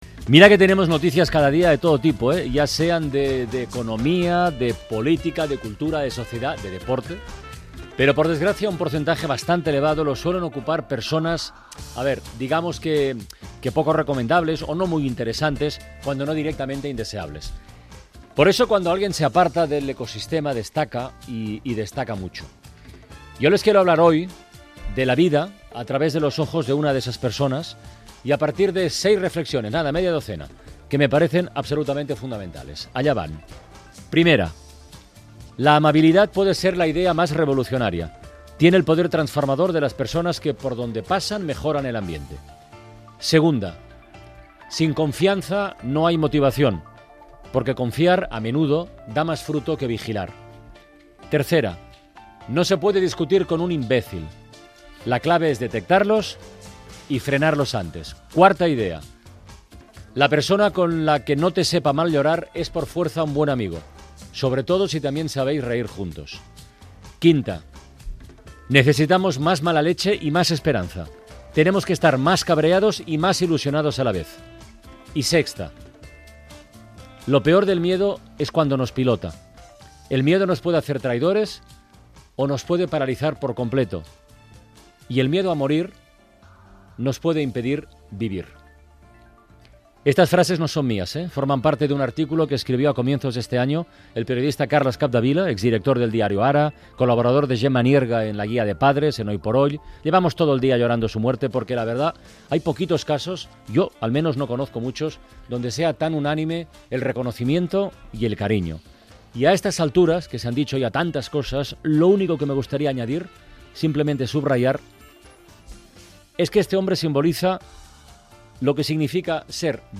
Lectura de reflexions del periodista Carles Capdevila que havia mort el dia abans i admiració per la seva figura
Entreteniment